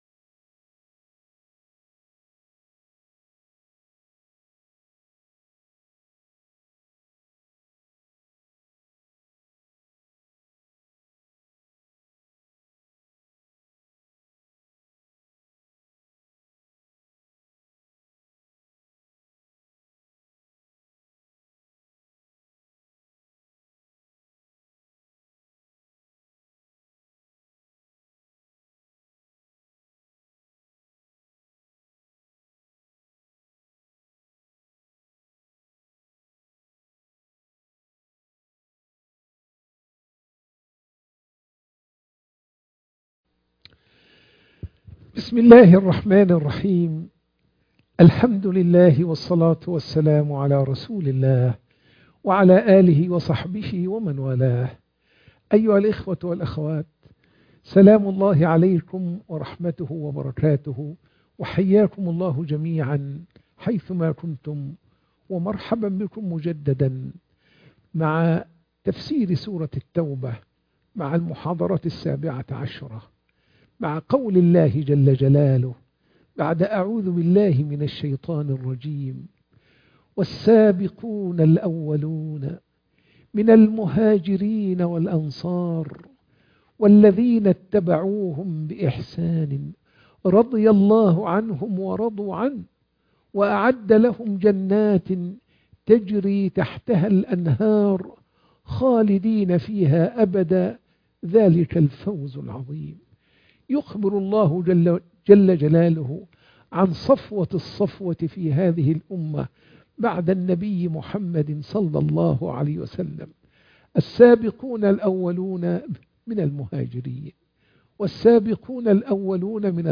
محاضرة التفسير